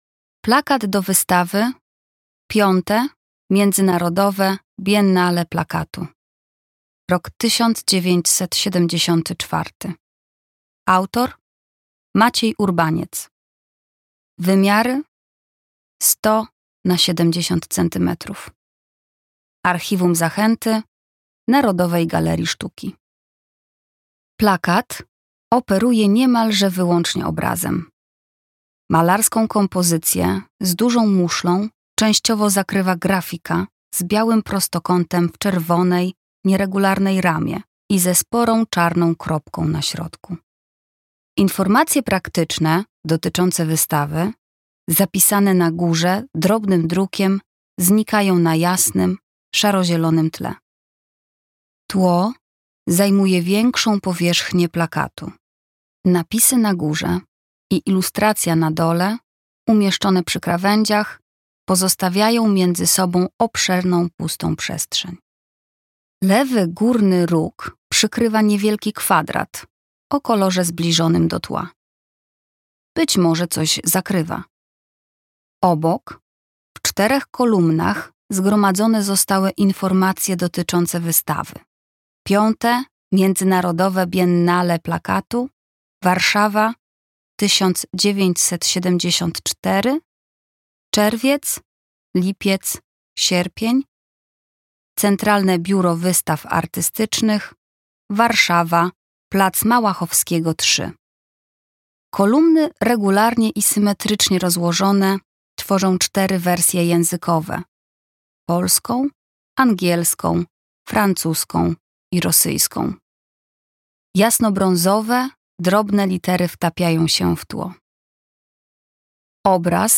audiodeskrypcja